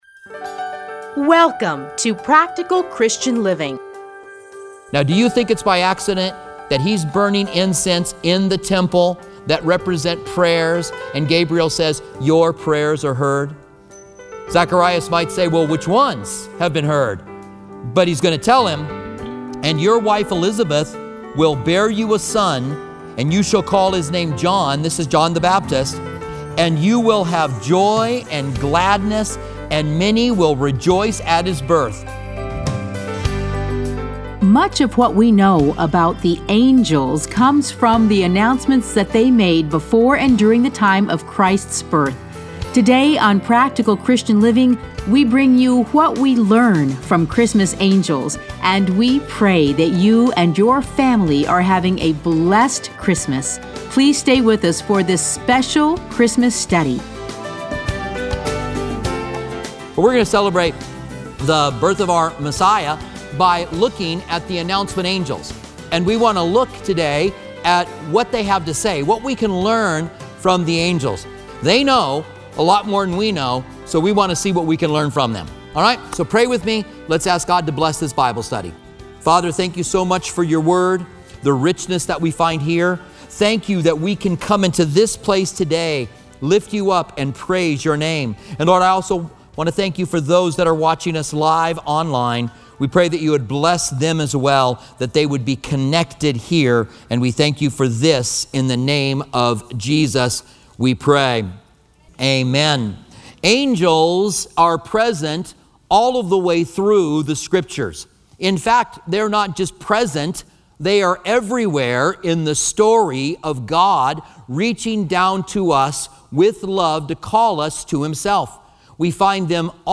Listen to a teaching from Luke 1, 2.